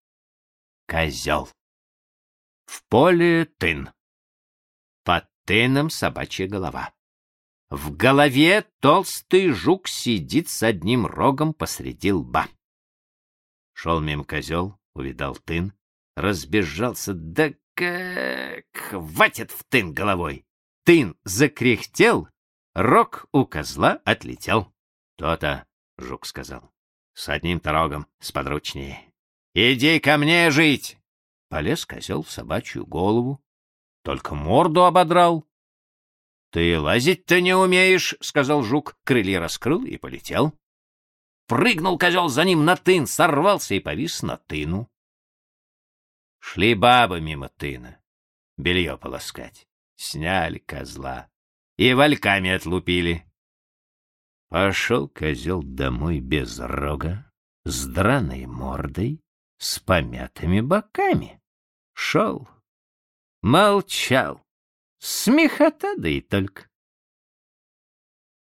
Козёл – Толстой А.Н. (аудиоверсия)
Аудиокнига в разделах